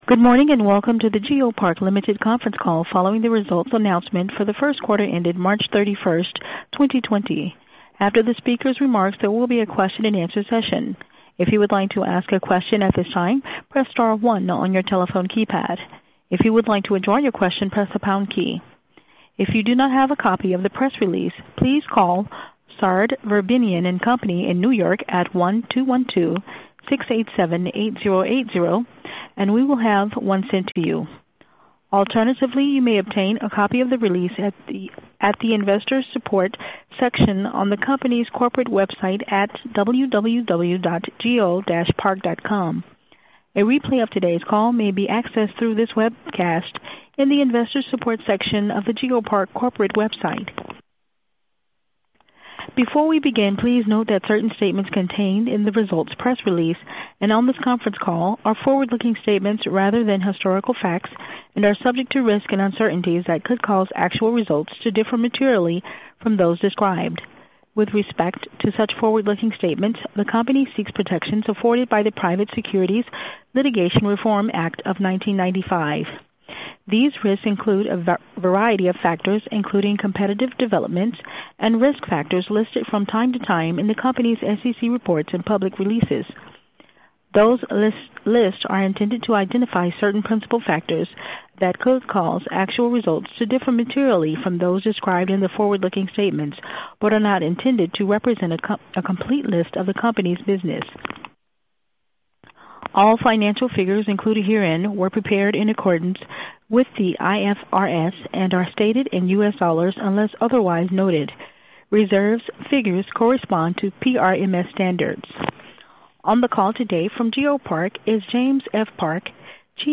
geopark-1q20-earnings-call.mp3